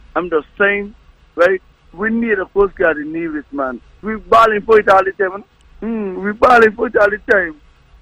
Following the positive end to the search and rescue operation where they men were found and transported back to shore, a caller on the August 6th edition of the On the Mark Program made this comment: